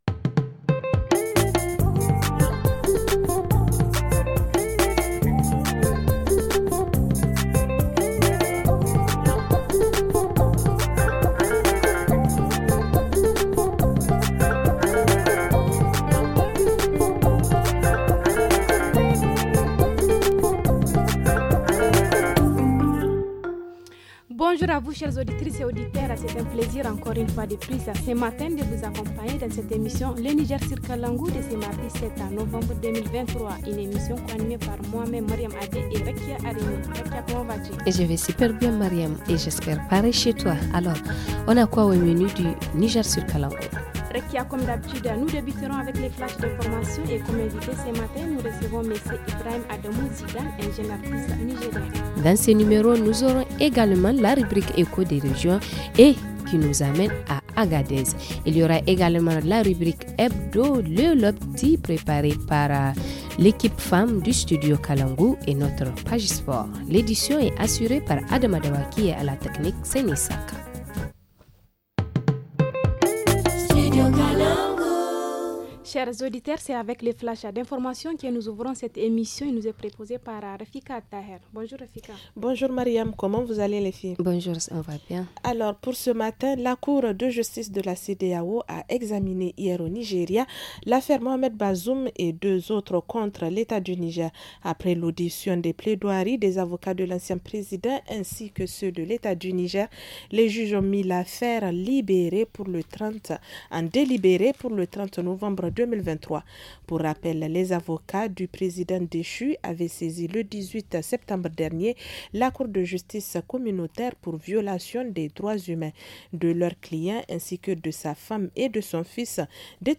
–Reportage région : l’importance sur l’accès des contraceptifs pour les familles qui le désire ;